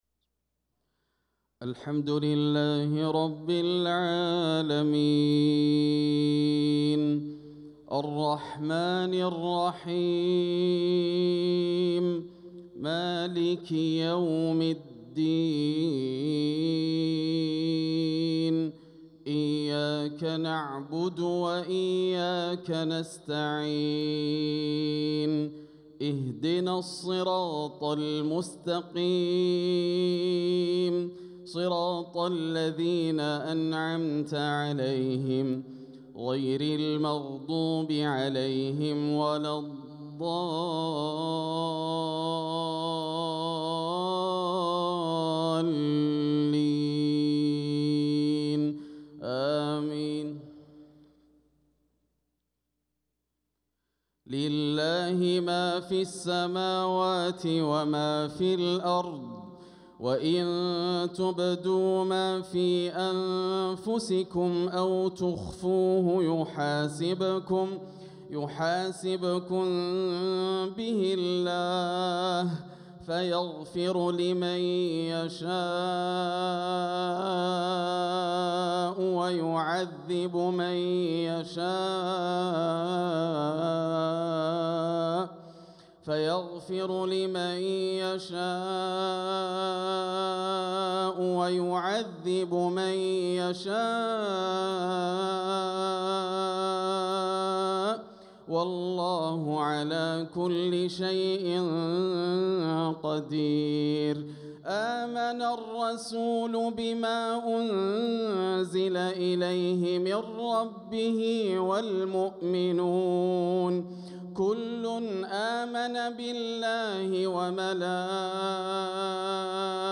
صلاة المغرب للقارئ ياسر الدوسري 3 ربيع الأول 1446 هـ